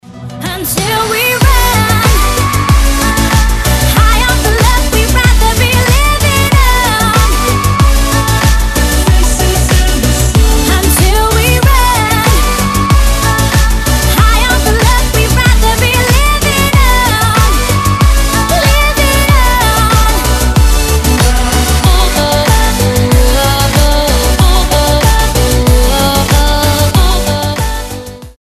• Качество: 256, Stereo
vocal
Pop & Dance